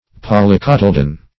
Search Result for " polycotyledon" : The Collaborative International Dictionary of English v.0.48: Polycotyledon \Pol`y*cot`y*le"don\, n. [Poly- + cotyledon: cf. F. polycotyl['e]done.]
polycotyledon.mp3